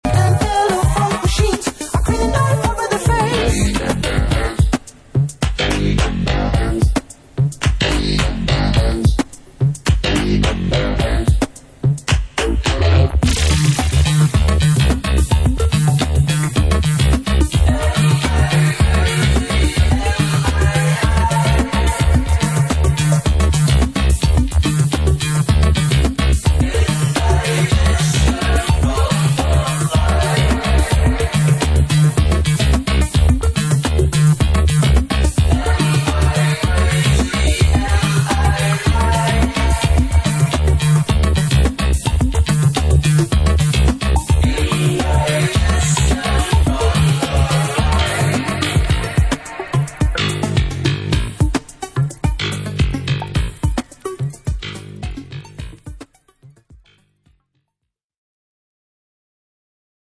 cosmic-electro-disco band